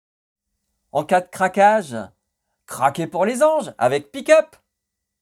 Advertising mockup KIT KAT in english
25 - 80 ans - Ténor